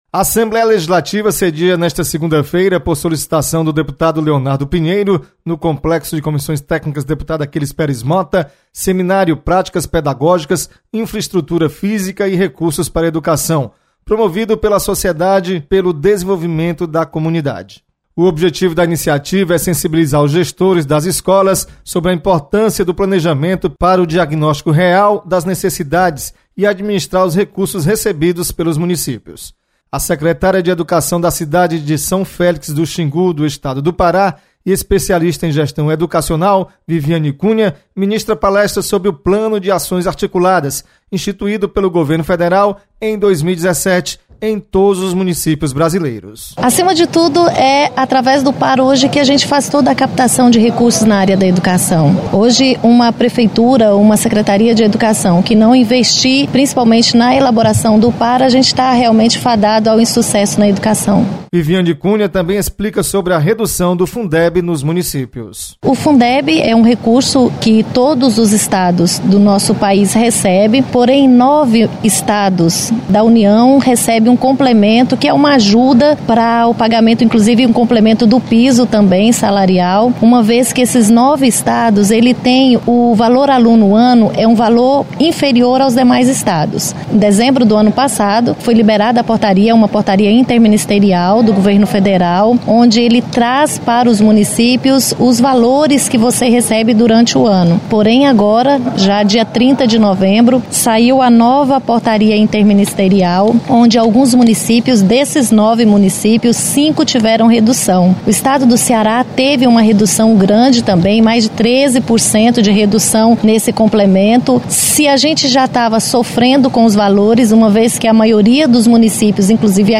Você está aqui: Início Comunicação Rádio FM Assembleia Notícias Educação